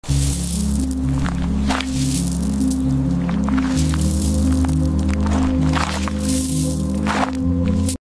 ASMR satisfying sound of pink sound effects free download
ASMR satisfying sound of pink crystals